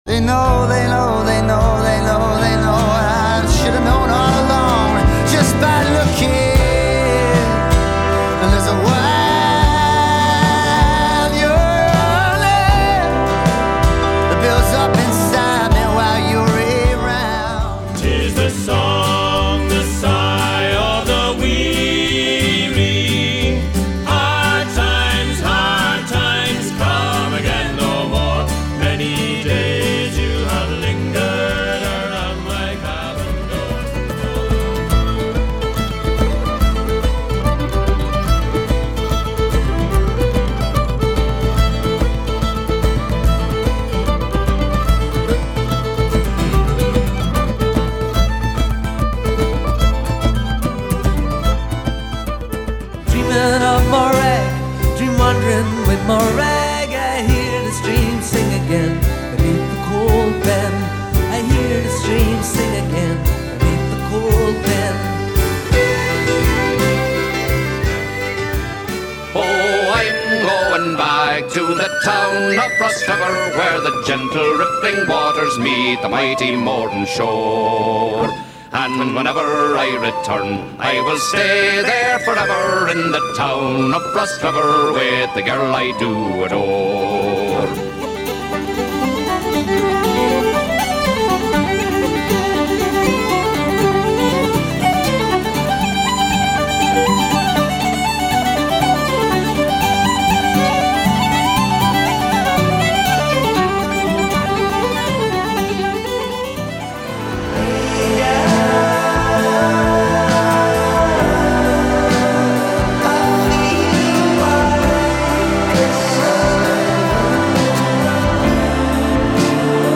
Celtic
Description: Light Traditional and Contemporary Celtic.